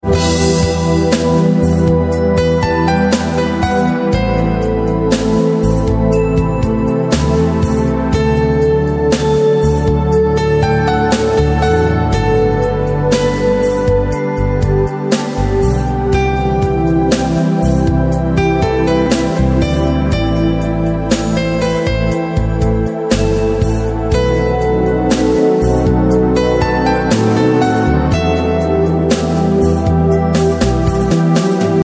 Категория: Классические рингтоны